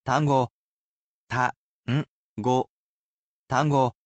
I will be sure to sound the main word out for you character by character.